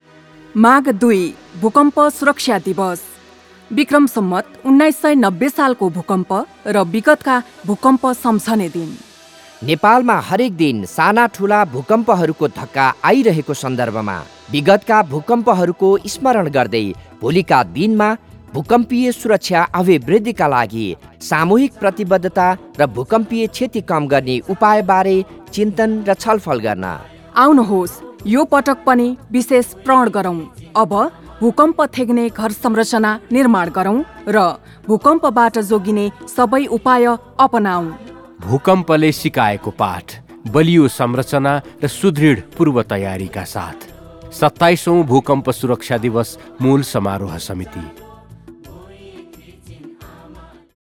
ESD Audio/Video PSA
Earthquake PSA 3.wav